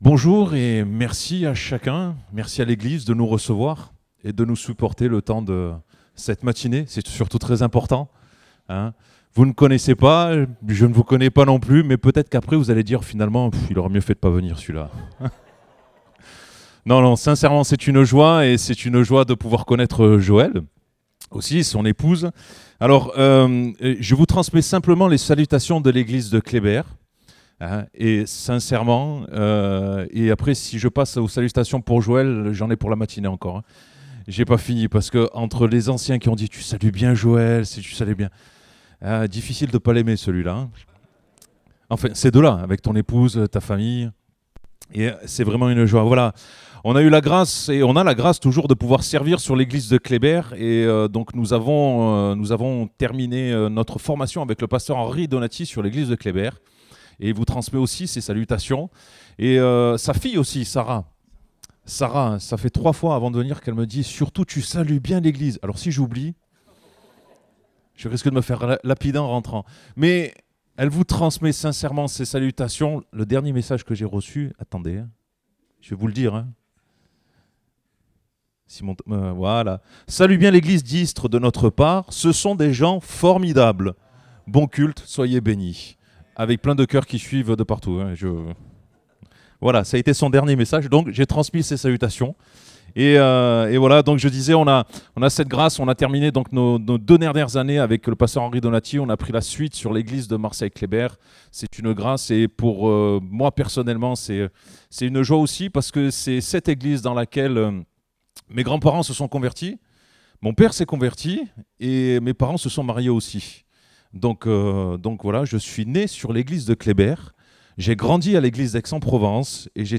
Date : 21 avril 2024 (Culte Dominical)